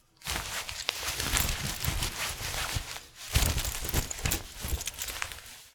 household
Cloth Wearing a Coat